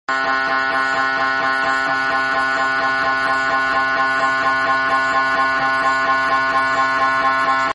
pada generasi mri terdahulu beginilah suara resonansi pada saat mri bekerja. suara bising yg dihasilkan berbeda beda setiap sequence yang dibuat.